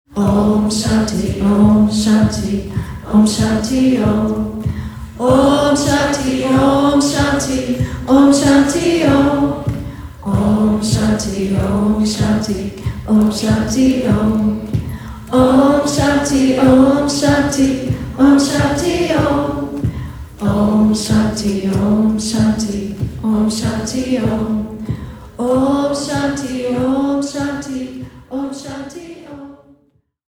This is a mood-booster for sure!